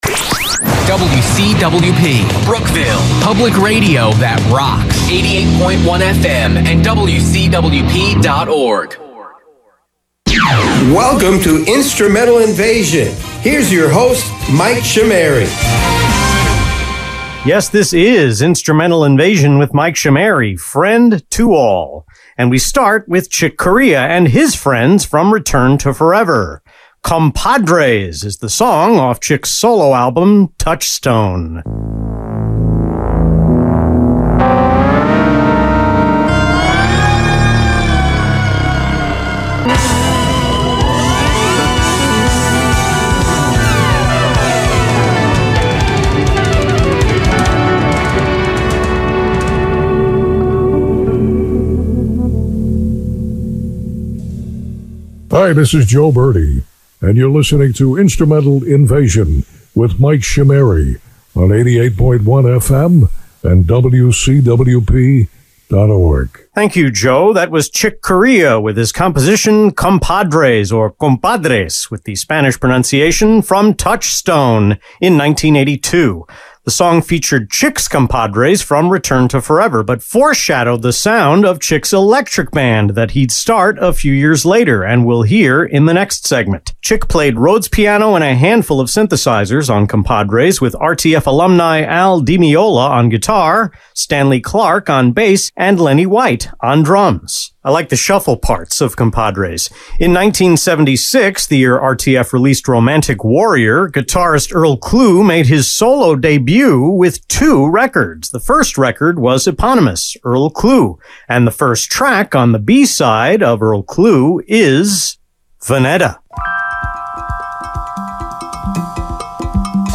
The November 2 Instrumental Invasion on WCWP was recorded and mixed entirely on September 19, the first one-day record/mix since June 27 when I worked on all of the August 17 show and the first segment of August 24. I made a timing error when remixing the third segment, which I didn’t notice until September 20.